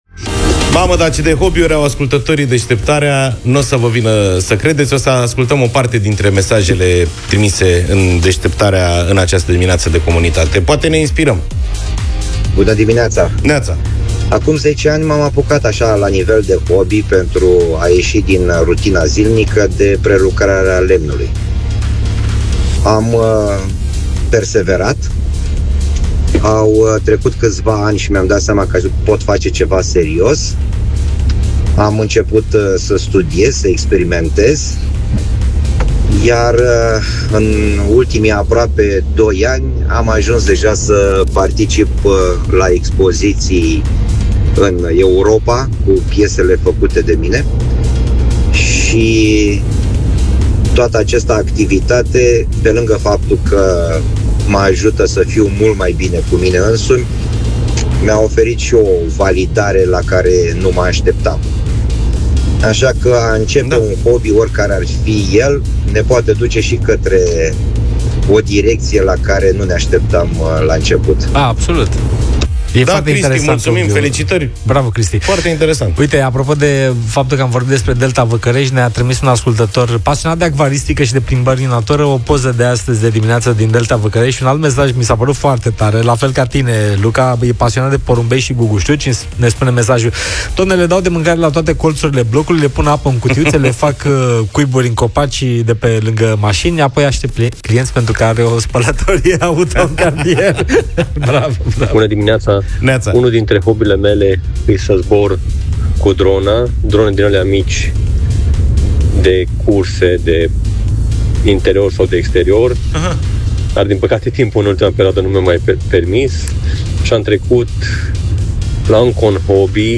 au discutat despre acest studiu împreună cu ascultătorii „Deșteptarea”, analizând cum putem transforma plimbările prin natură într-un exercițiu pentru creier.